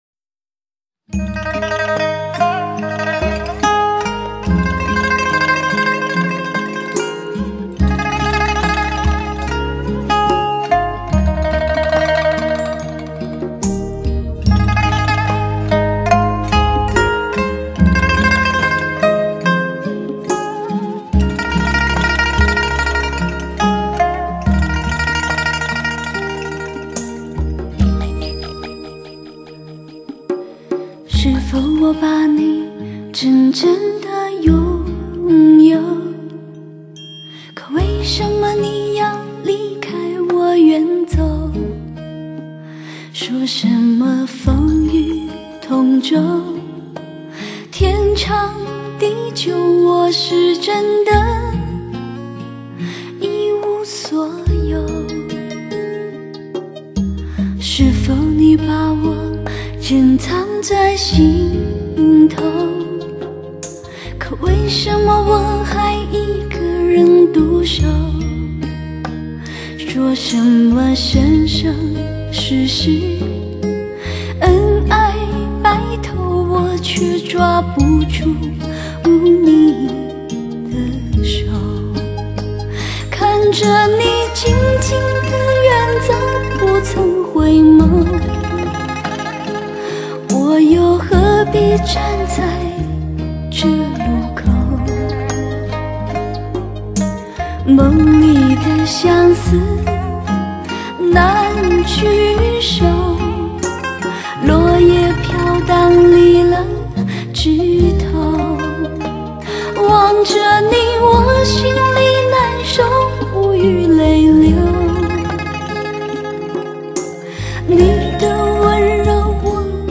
“气声”演唱方法，迷蒙而柔和，营造了清新的格调、别致的韵味。
串串珠泪串起的流畅旋律，袅袅余音犹绕梁，如痴如醉的闺怨诉语，悸动独守的落寞。